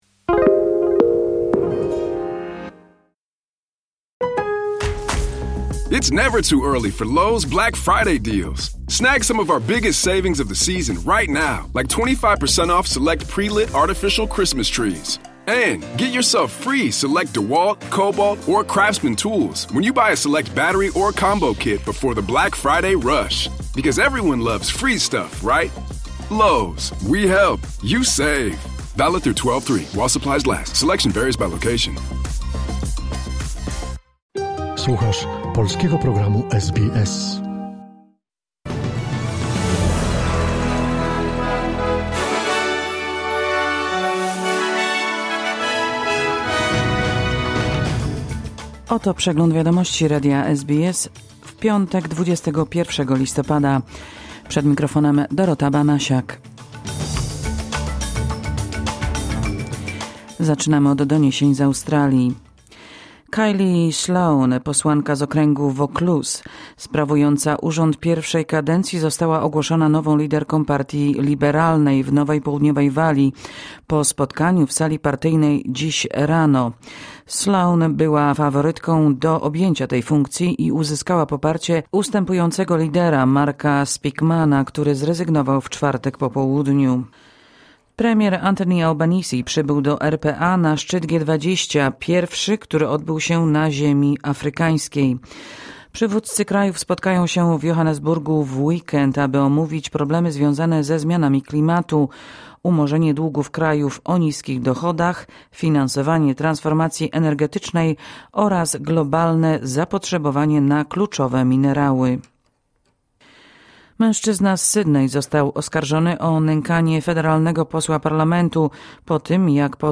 Wiadomości 21 listopada SBS News Flash